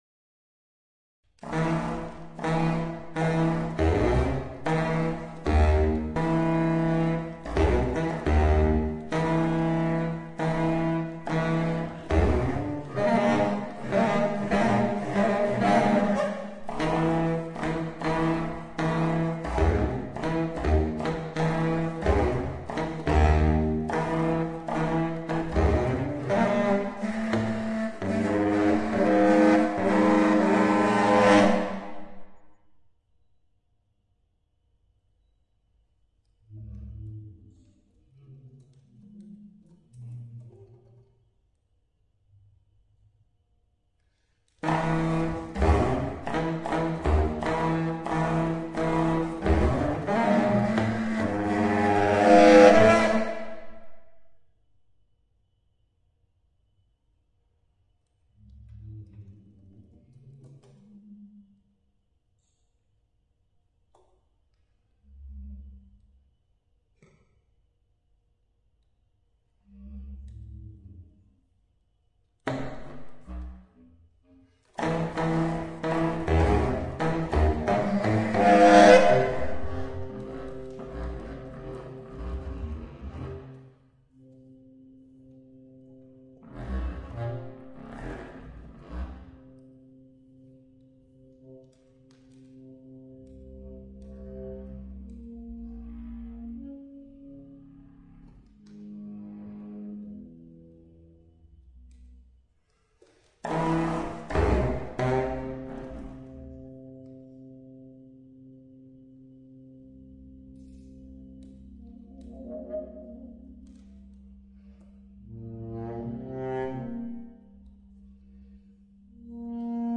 baritone saxophone
contrabass clarinet youtube YouTube